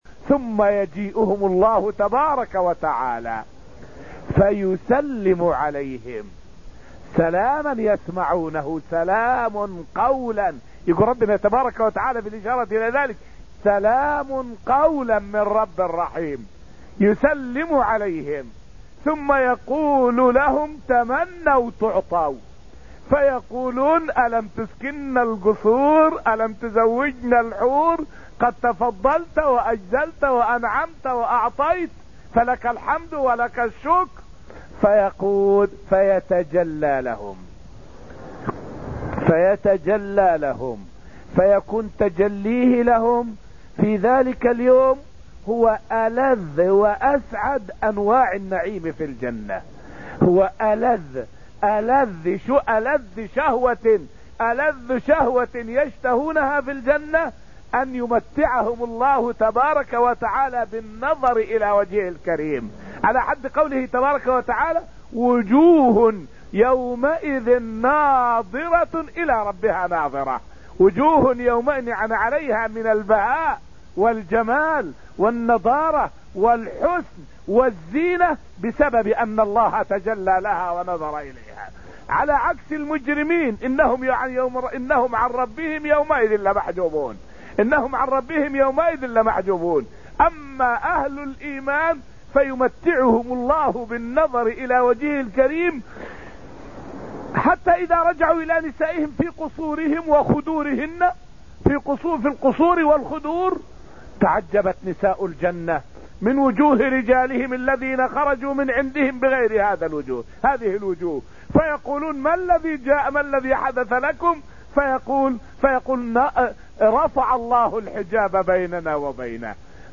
فائدة من الدرس الرابع عشر من دروس تفسير سورة الحديد والتي ألقيت في المسجد النبوي الشريف حول سلام الله على أهل الجنة.